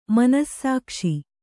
♪ manassākṣi